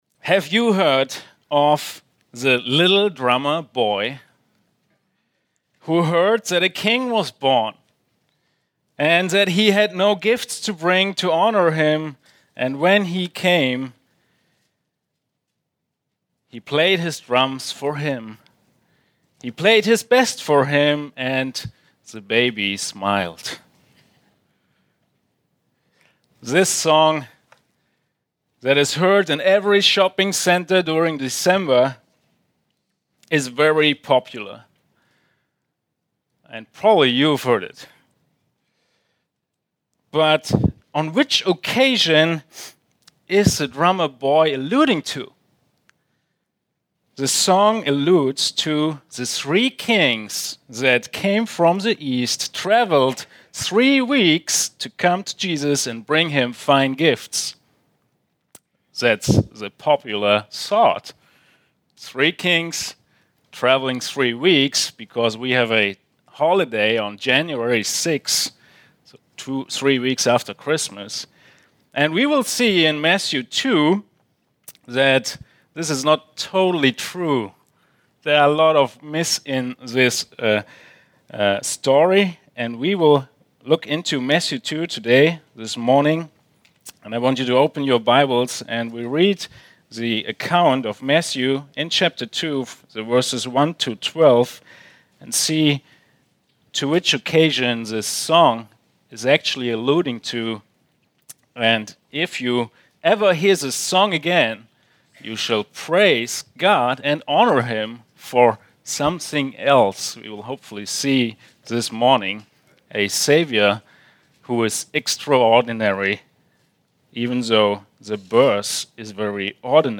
Extraordinary Savior Guest Preacher